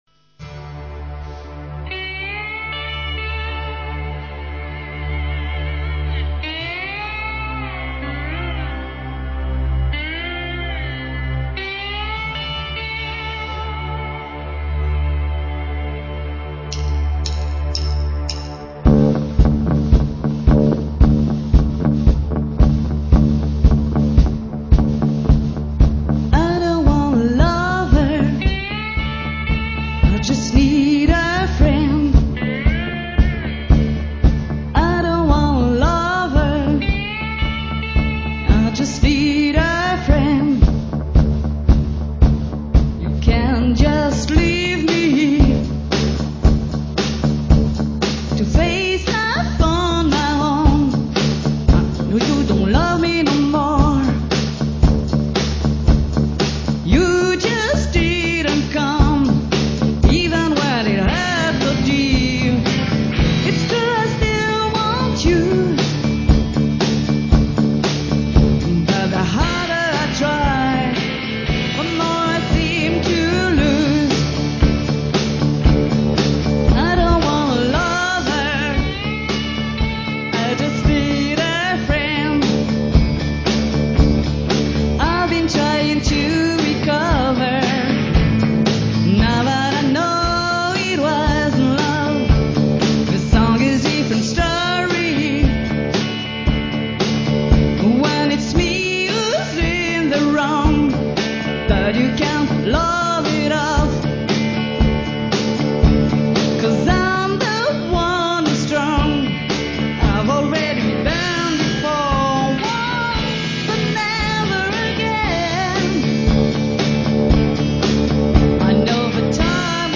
guitares
batterie